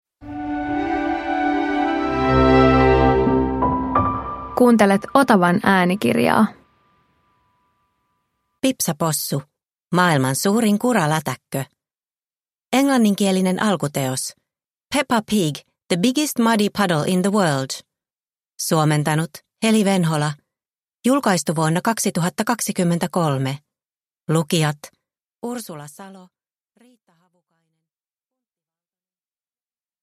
Pipsa Possu - Maailman suurin kuralätäkkö – Ljudbok – Laddas ner